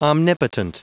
Prononciation du mot omnipotent en anglais (fichier audio)
Prononciation du mot : omnipotent